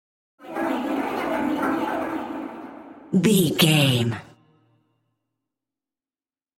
High Witch Chatting.
Sound Effects
Atonal
ominous
eerie
creepy